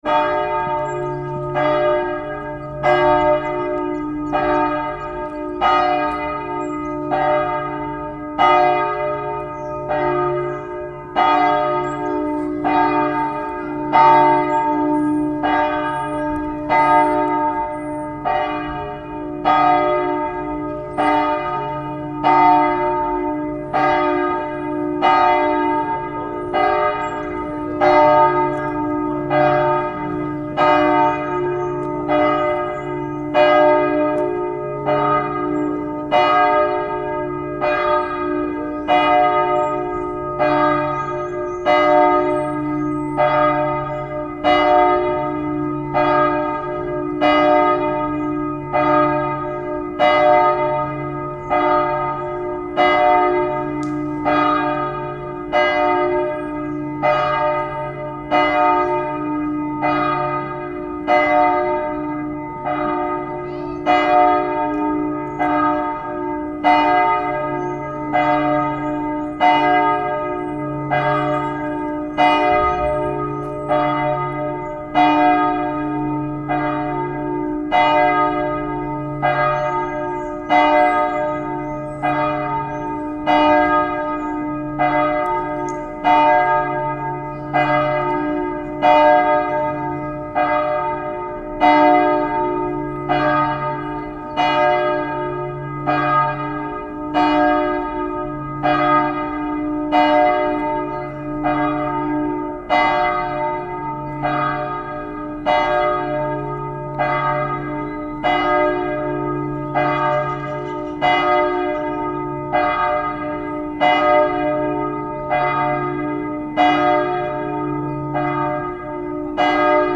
Authentic Village Church Bell Tolling Sound Effect
An authentic village church bell tolling sound effect recorded on a town square in a small European village. The long church bell ringing creates a traditional atmosphere while birds chirp and people talk quietly in the background. This natural ambience is perfect for documentaries, films, historical scenes, games, and multimedia projects.
Authentic-village-church-bell-tolling-sound-effect.mp3